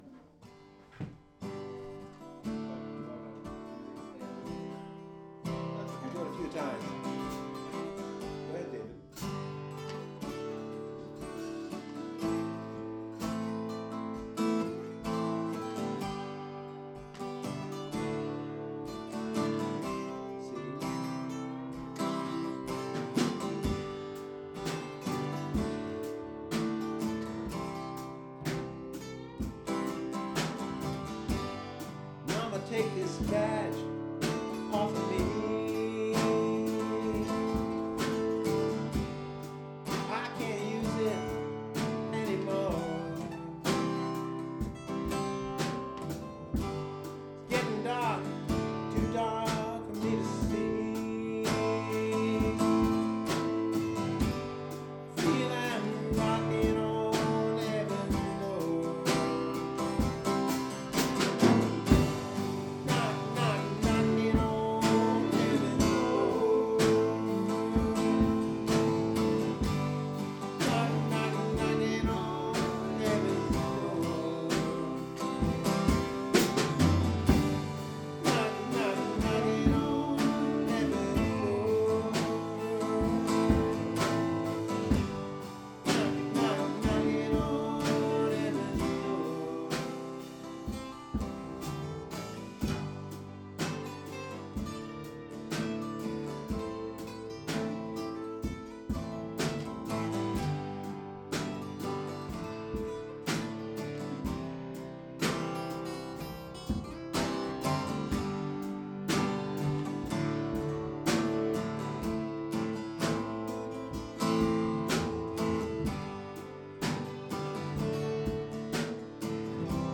Group Jam Knock, Knock, Knockin